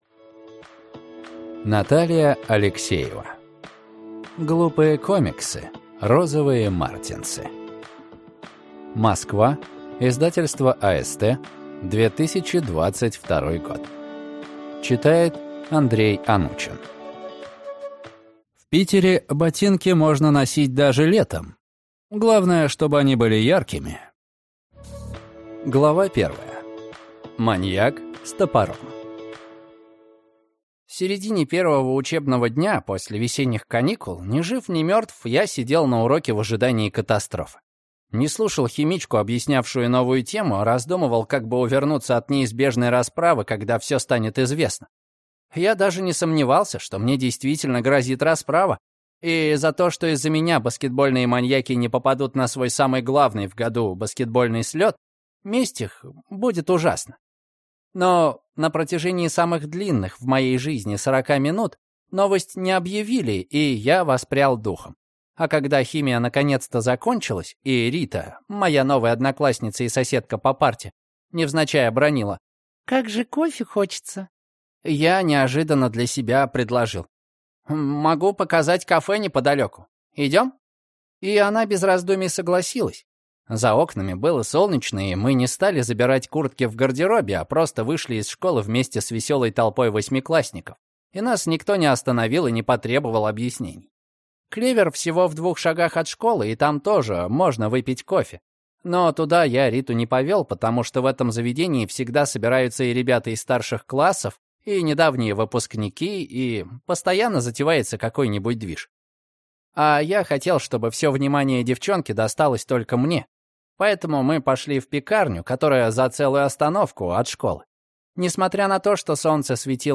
Aудиокнига Глупые комиксы